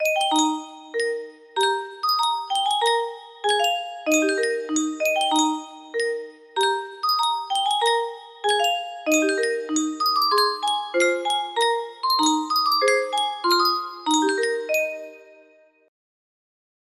Yunsheng Music Box - The Marines' Hymn Y480 music box melody
Full range 60